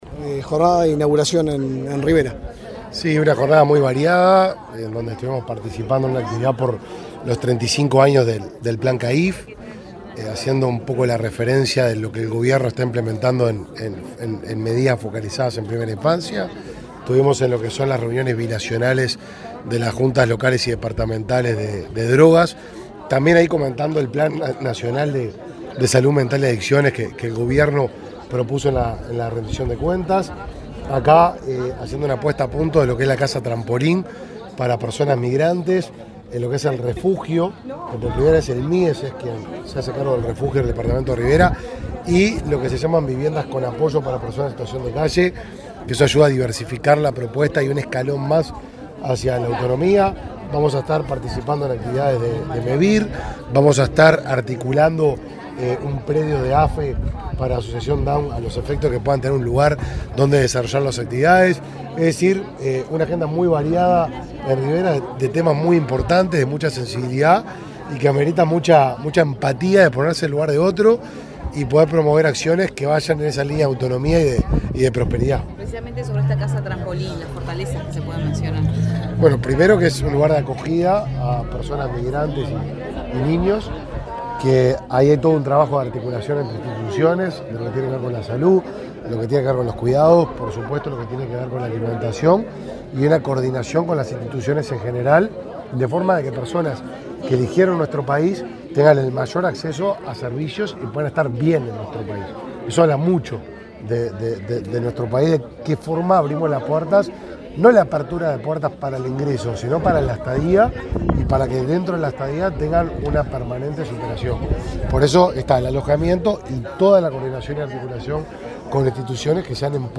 Declaraciones del ministro de Desarrollo Social, Martín Lema
Luego dialogó con la prensa.